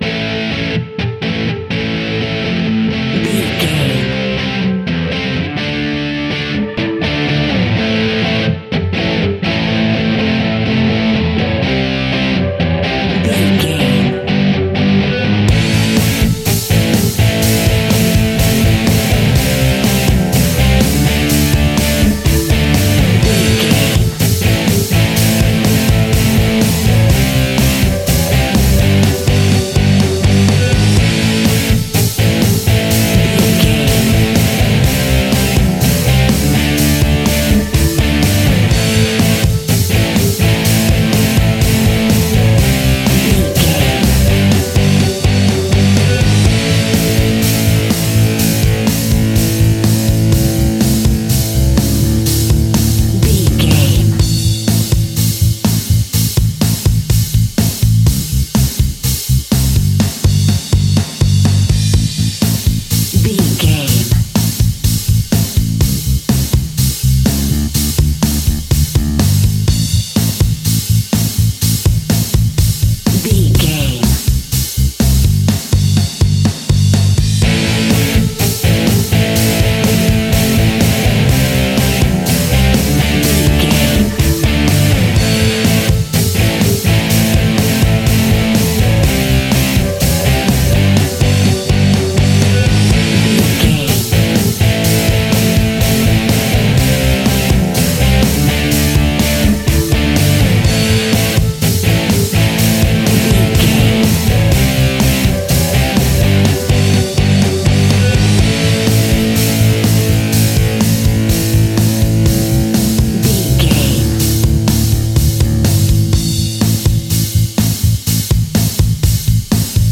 Epic / Action
Fast paced
Aeolian/Minor
hard rock
heavy metal
blues rock
distortion
Rock Bass
Rock Drums
heavy drums
distorted guitars
hammond organ